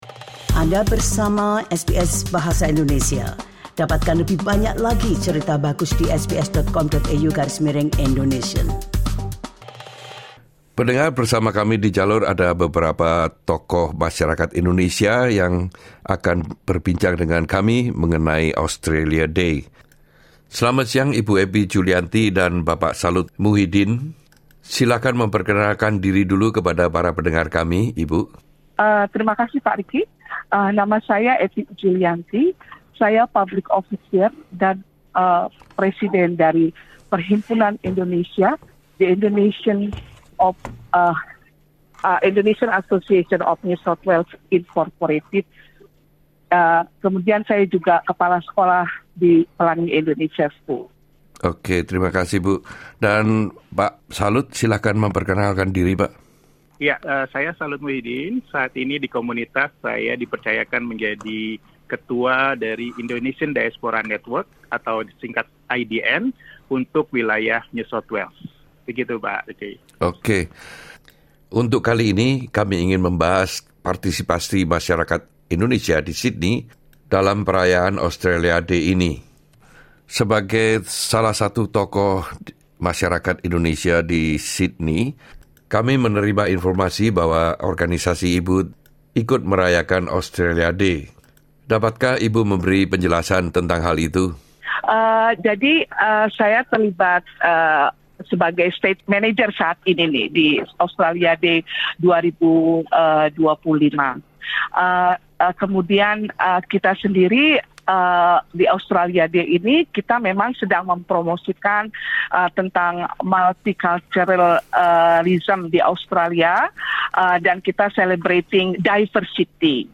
SBS Indonesia mewawancarai